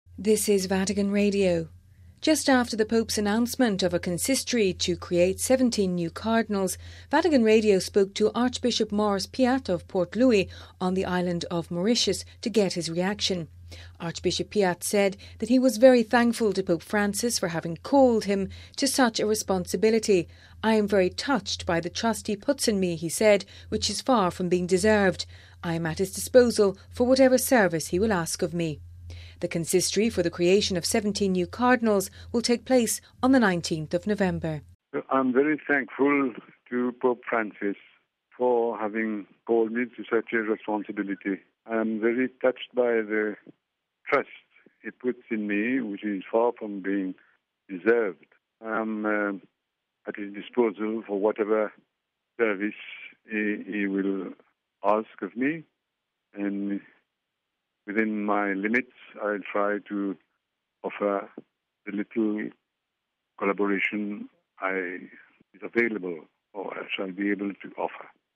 (Vatican Radio) Just after the Pope’s announcement on Sunday of a consistory to create 17 new Cardinals, Vatican Radio spoke to Archbishop Maurice Piat of Port-Louis on the island of Mauritius to get his reaction.